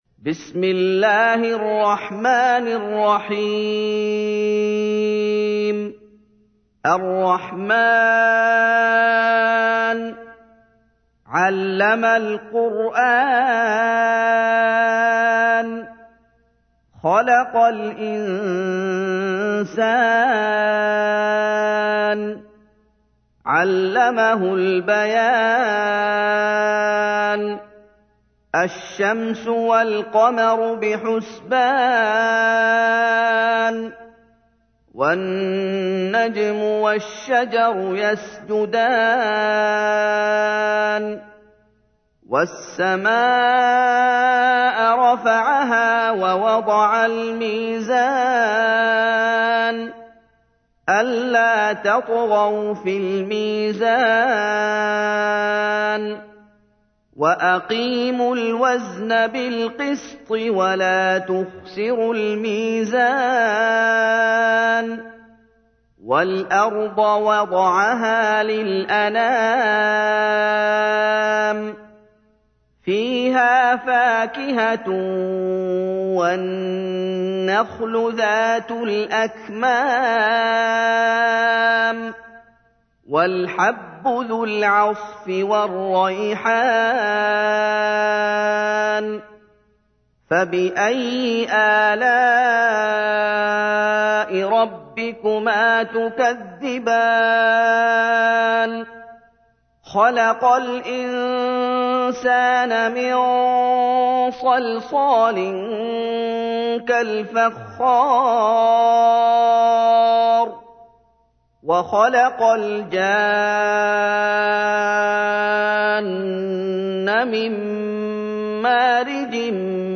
تحميل : 55. سورة الرحمن / القارئ محمد أيوب / القرآن الكريم / موقع يا حسين